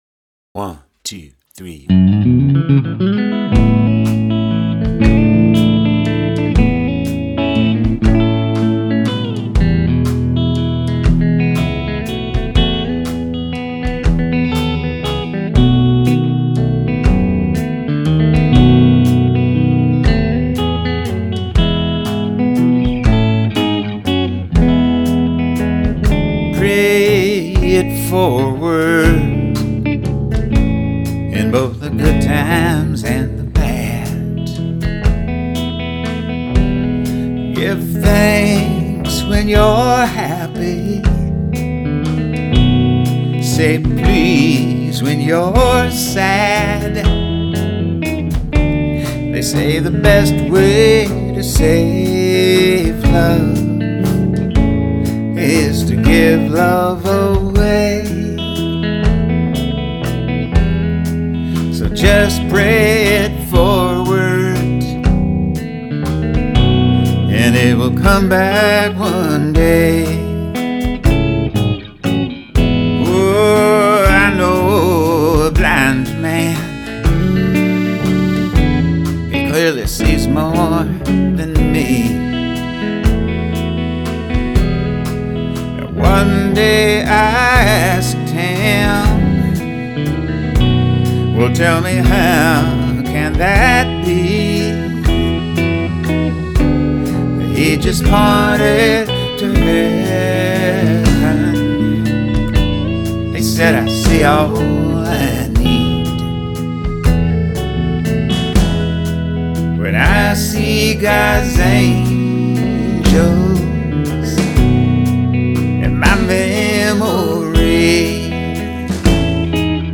Okay, shoot me. I played the accordion.